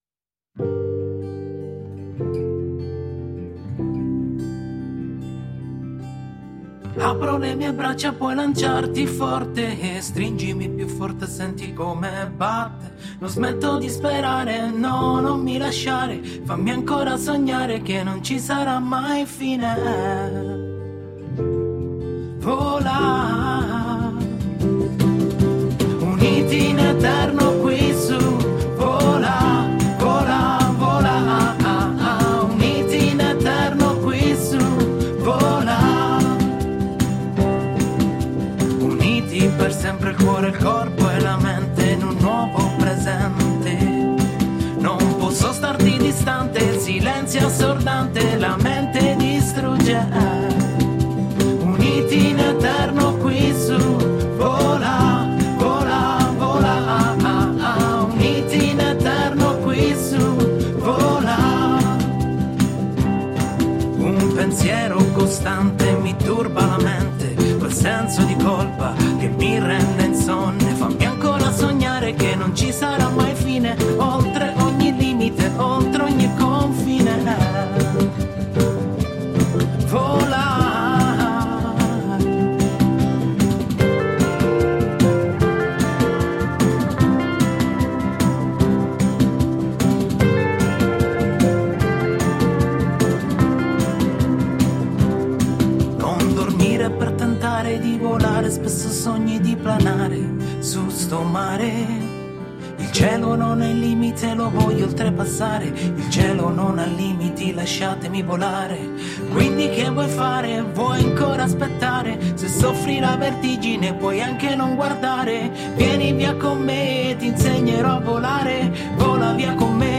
Relaxed pop and reggae music made in italy.